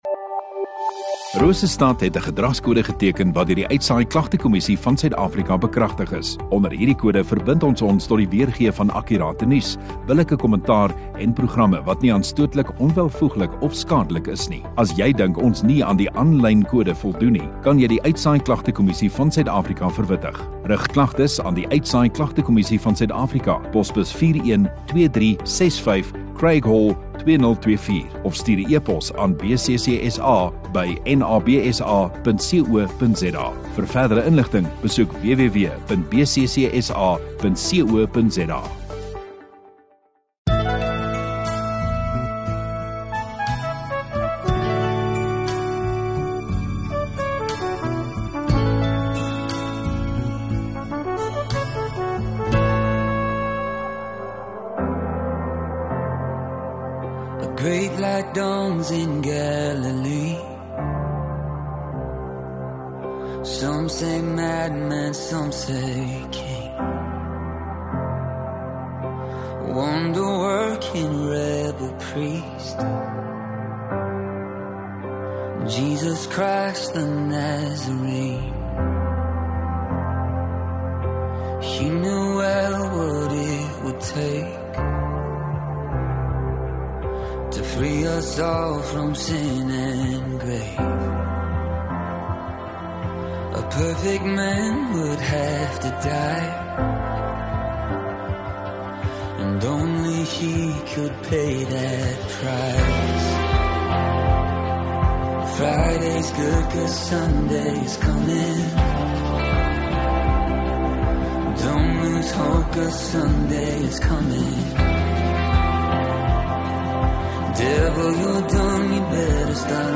29 Dec Sondagoggend Erediens